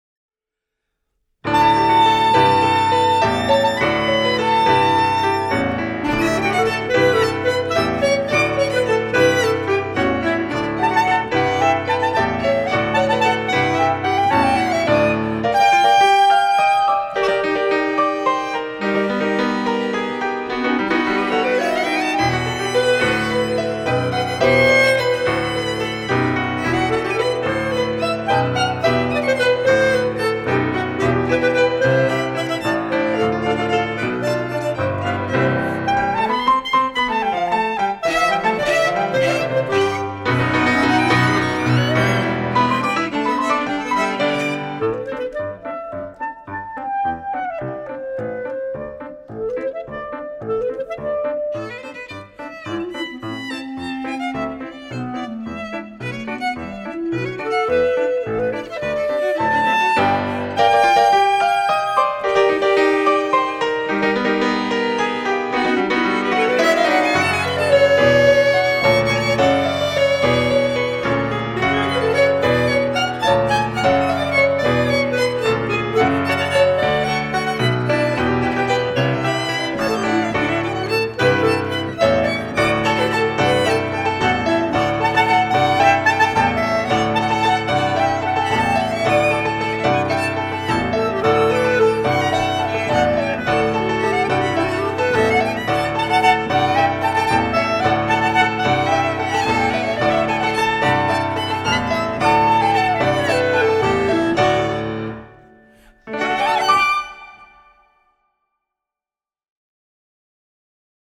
Darius Milhaud Suite op. 157b for violin, clarinet and piano
at ECSU MVA department recording studio.
Clarinet
Violin
Piano